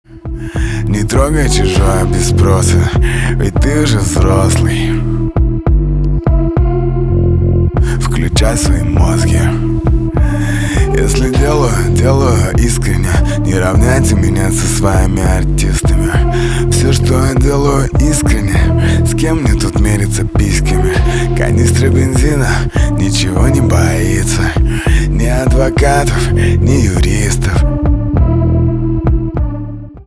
русский рэп
басы